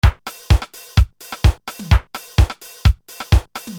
Drumcomputer
Revolutionär war auch die LM-1 und die hier gezeigte Linndrum von Linn, die Anfang der 80er erschien und erstmals gesampelte Klänge an Bord hatte.
Linndrum 1